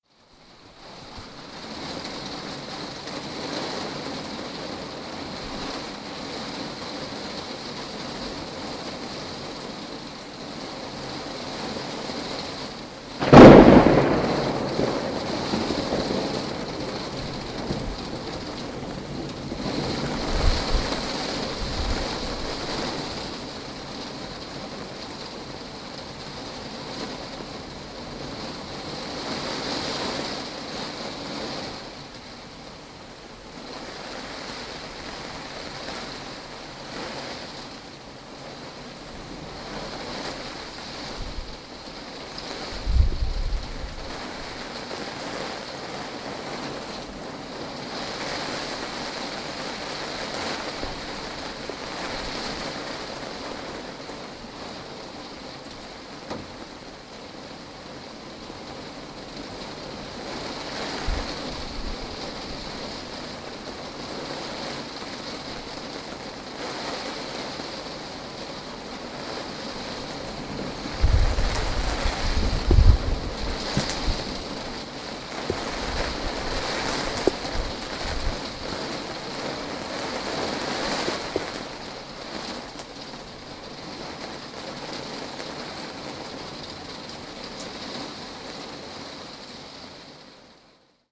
So klingt der Sommer auf dem Balkon bei Regen
Das Geräusch von Sommergewitter auf dem Balkon. Donnergrollen und der Regen trommelt aufs Dach. Einige Tropfen gehen direkt auf das Mikrofon.
Regen-Balkon-Gewitter-Leipzig.mp3